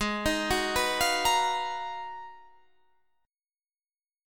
Abm13 Chord